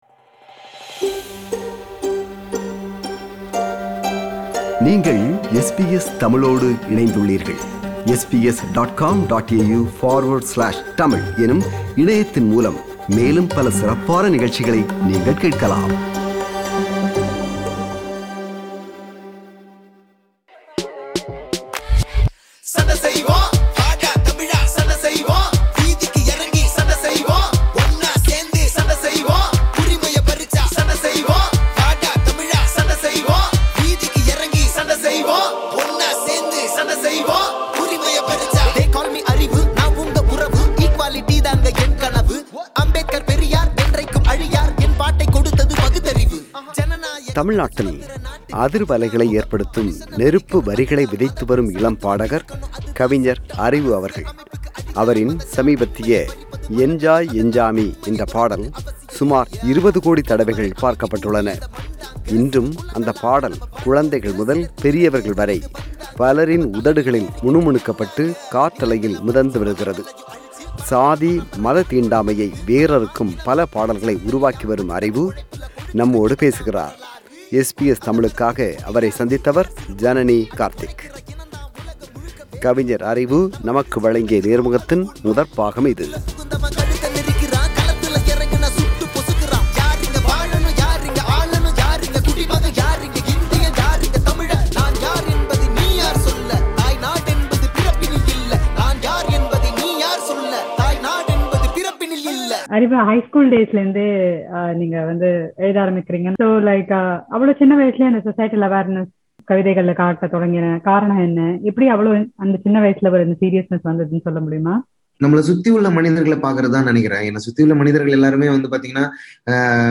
சாதி, மத தீண்டாமையை வேரறுக்கும் பல பாடல்களை உருவாக்கிவரும் அறிவு அவர்கள் நம்மோடு பேசுகிறார்.